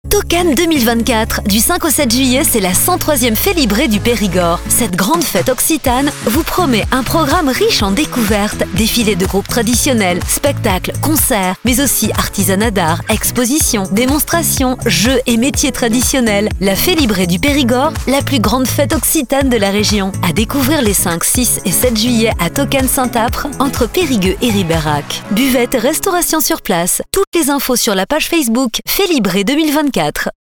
réalisé par un studio